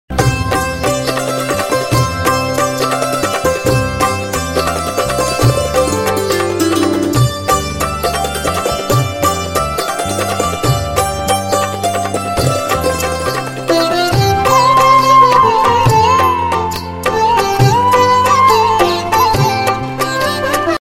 Ringtones Mp3